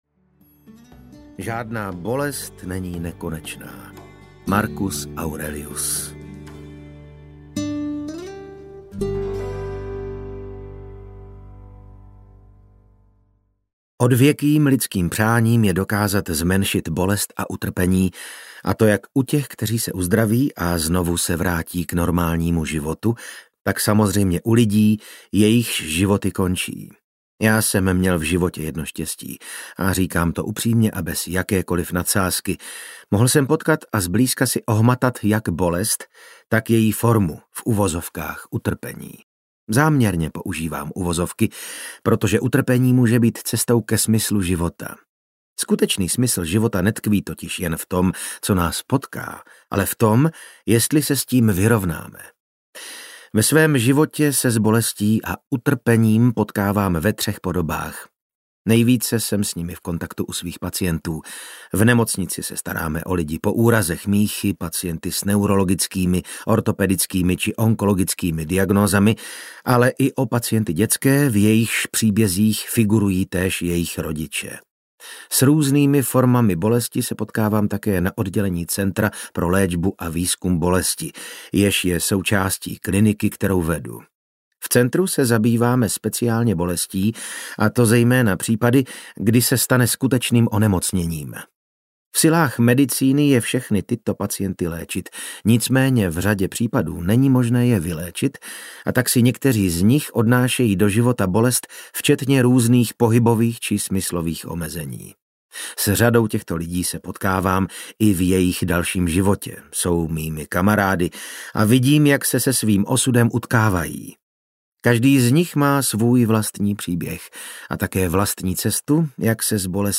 Labyrint bolesti audiokniha
Ukázka z knihy
• InterpretVasil Fridrich